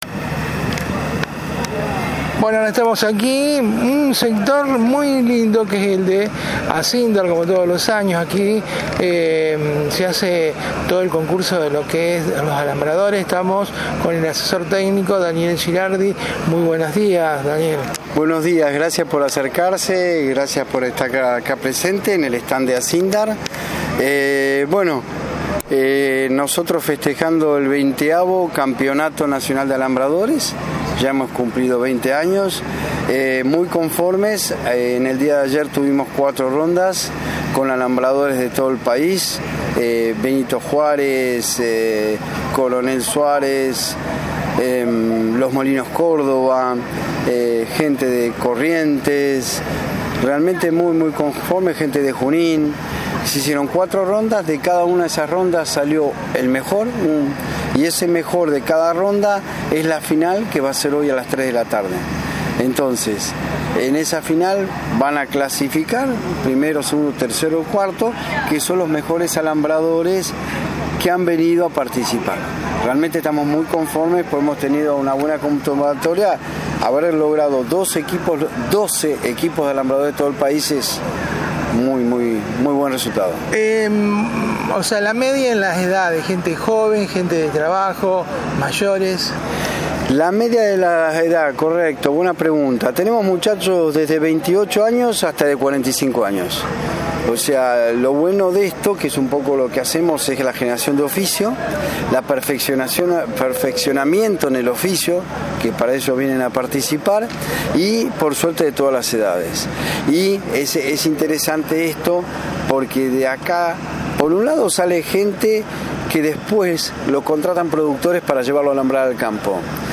En el marco de Expoagro se desarrolló nuevamente el tradicional Campeonato Nacional de Alambradores, iniciativa impulsada por Acindar Grupo ArcelorMittal, que año tras año reúne a especialistas del oficio provenientes de distintas regiones del país.